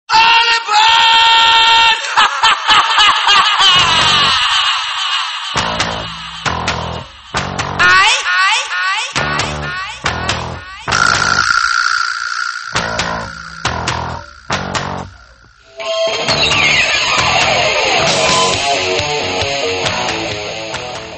Dance Ringtones